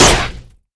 rifle_hit_metal2.wav